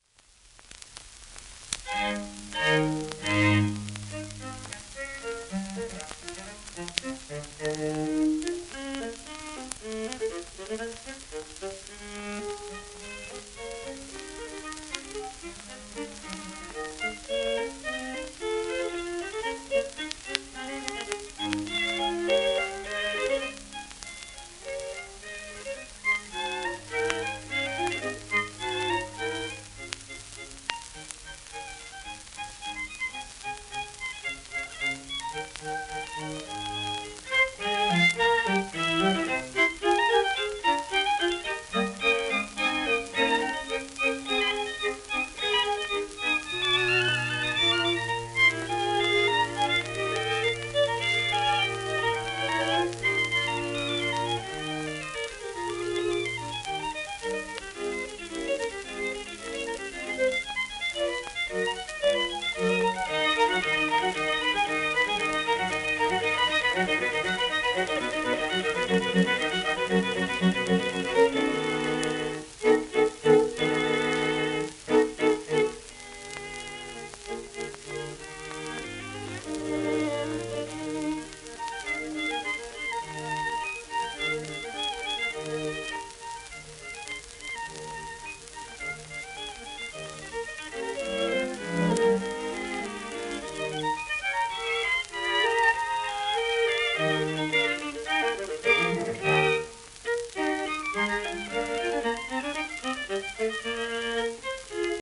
1926年録音
シェルマン アートワークスのSPレコード